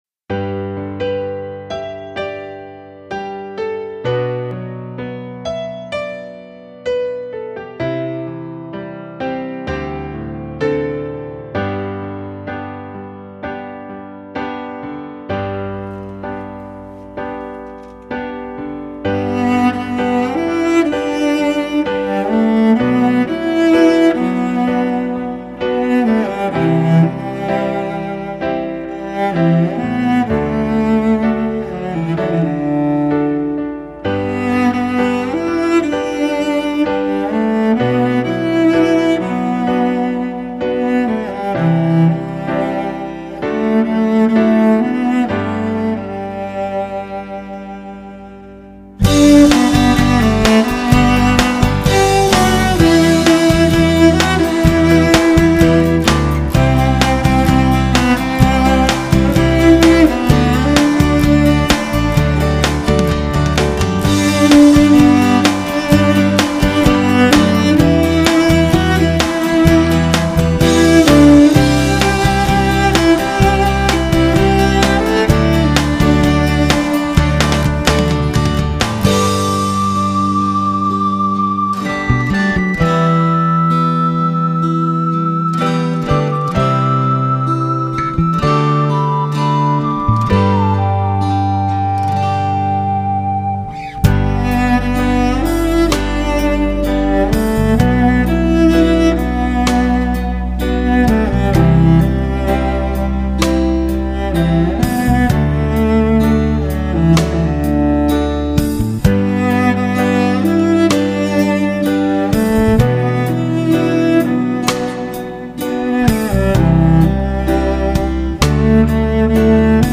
Nature Music